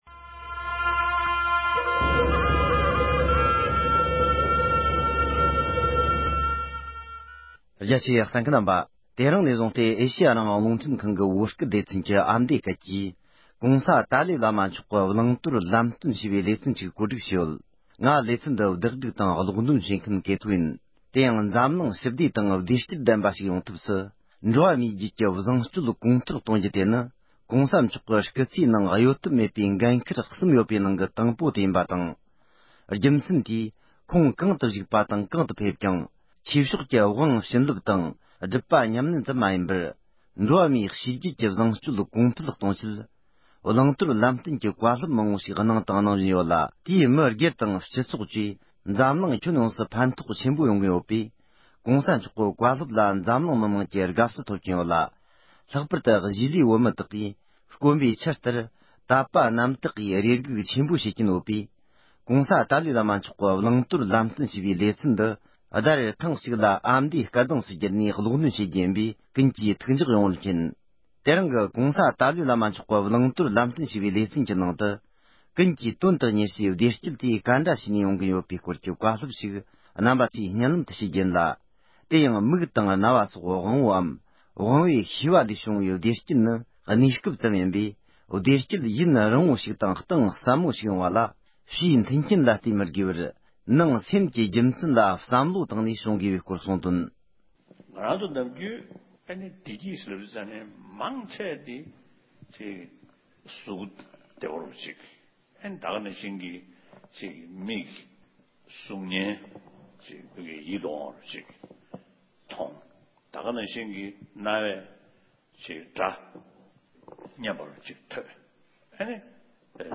༸གོང་ས་མཆོག་གིས་བསྩལ་བའི་ཀུན་གྱི་དོན་དུ་གཉེར་བཞིན་པའི་བདེ་སྐྱིད་དེ་ཇི་ལྟར་ཡོང་དགོས་པ་ཞེས་པའི་བཀའ་སློབ།